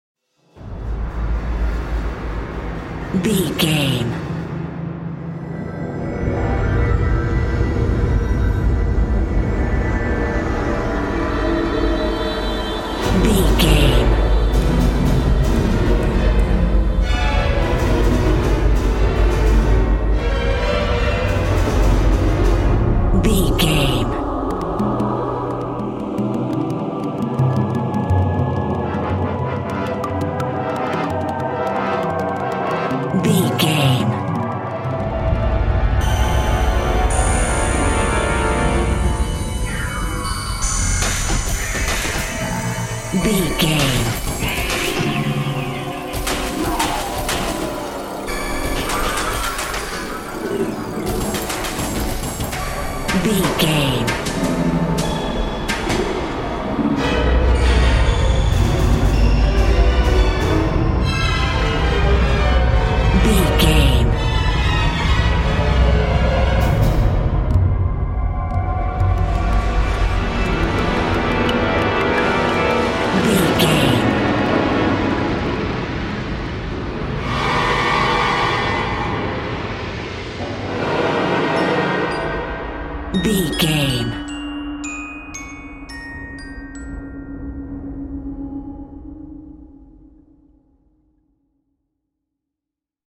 Survival horror
Atonal
D
percussion
strings
ominous
dark
suspense
haunting
tense
creepy
spooky